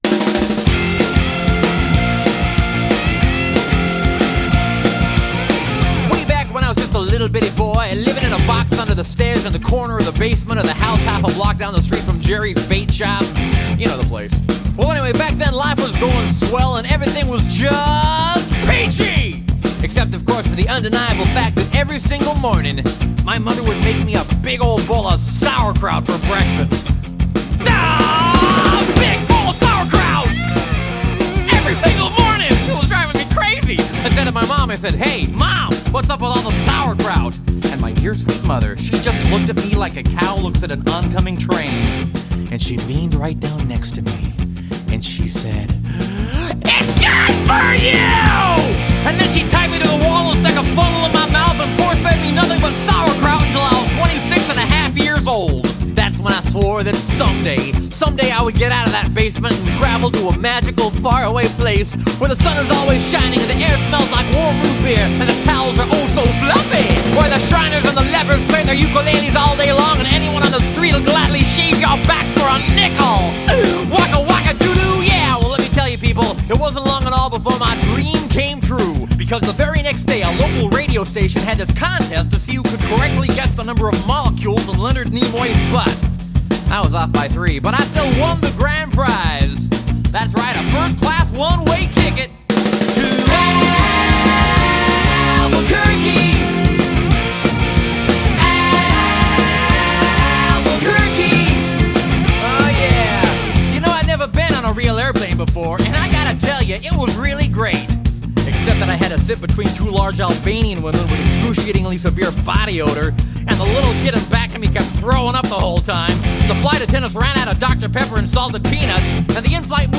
BPM 62-275
Audio Quality Perfect (High Quality)